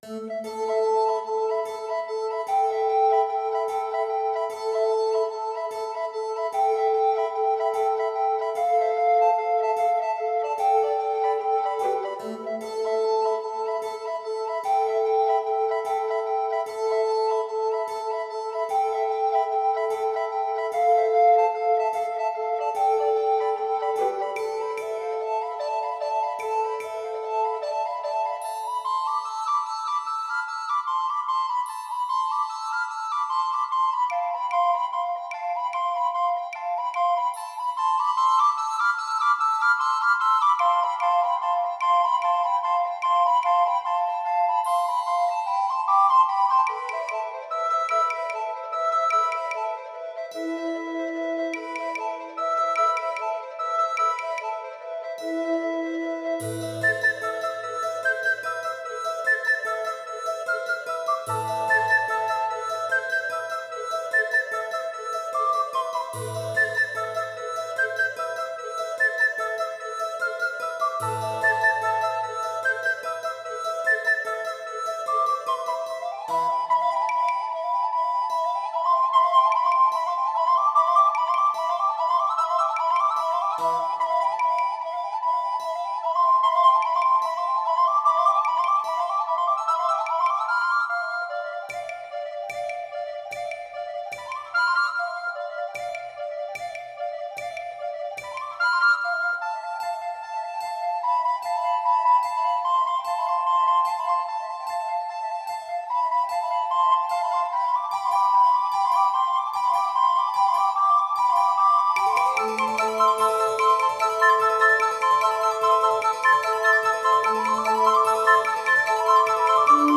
verze pro 8 zobcových fléten, cembalo a perkuse z roku 2024
nahrávka z koncertu